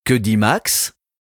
Dog - Living Room - Dog Language [Quiz] | Purina FR
dog_languages_quiz_question2_fr_1.ogg